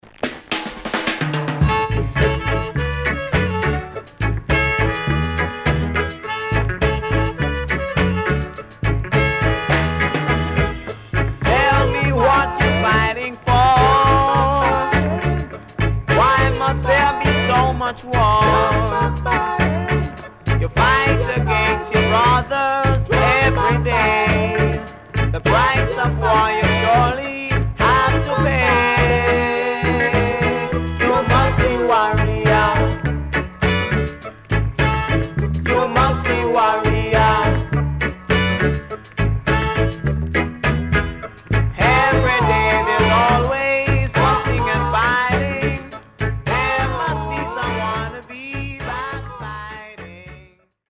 Good Horn Inst & Nice Early Reggae Vocal .W-Side Good
SIDE A:所々チリノイズがあります。